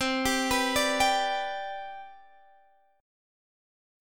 CM7sus2 chord